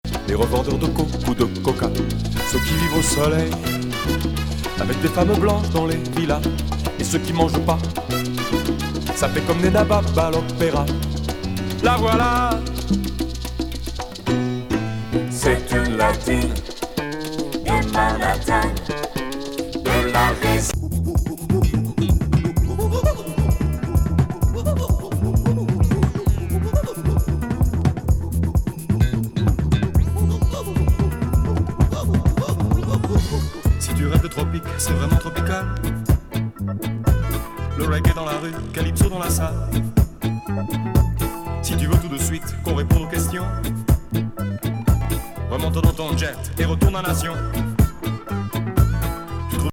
直球ラテン
レゲエ・ロック